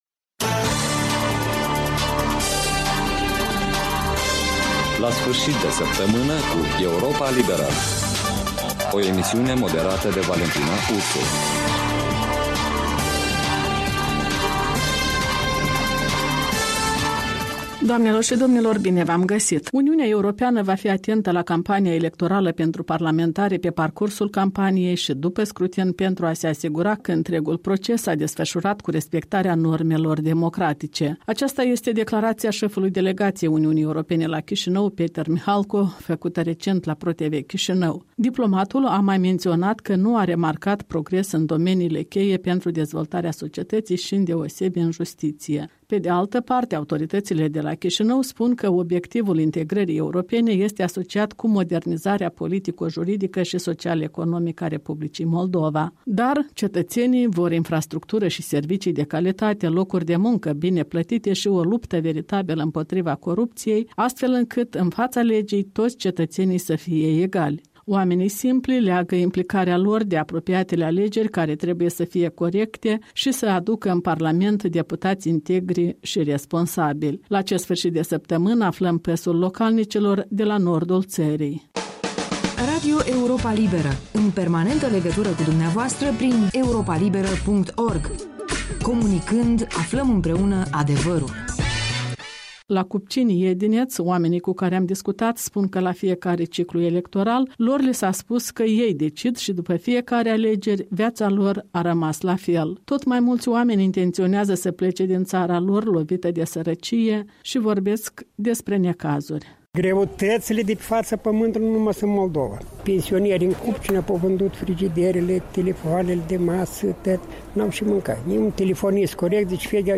moldoveni din Cupcini, Edineț